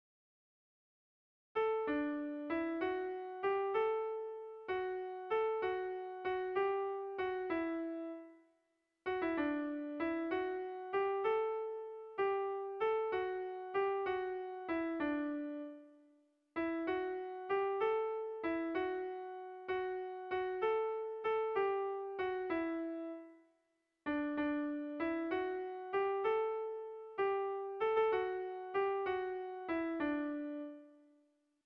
Zortziko txikia (hg) / Lau puntuko txikia (ip)
A1A2BA2